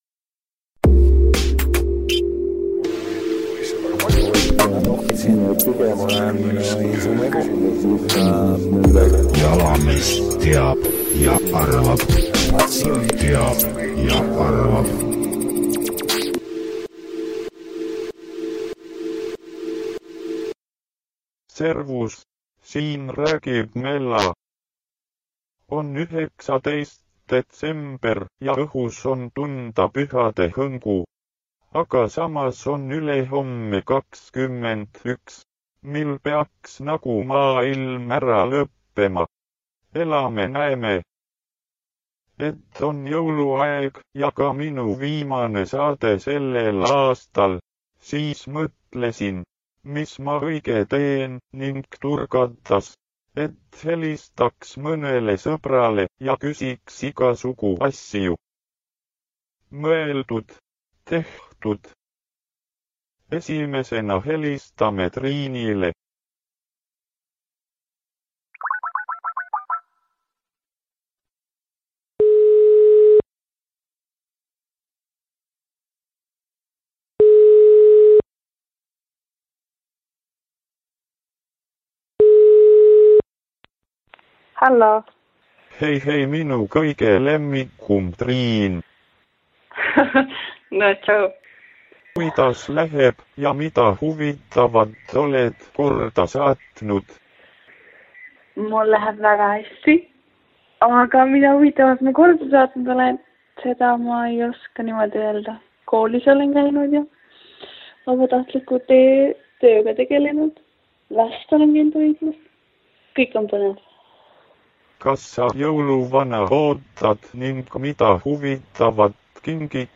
Helistame sõpradele ja laseme jõululuuletusi lugeda 🙂